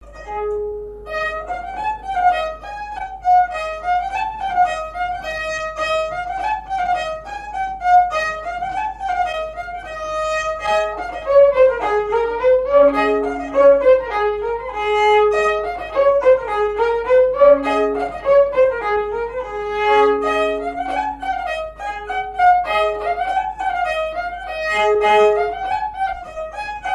Saint-Martin-des-Tilleuls
danse : branle : avant-deux
Musique à danser, rondes chantées et monologue
Pièce musicale inédite